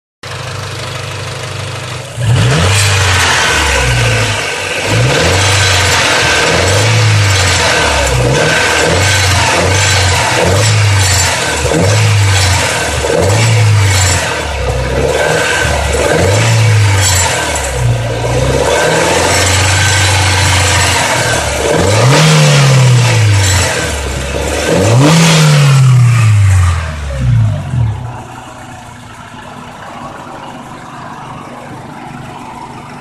Nene das ist ein rasseln/klirren wie wennde schrauben in ner blechdose schuettelst
Motor
Das klackern war genau so wie bei dir das dritte video.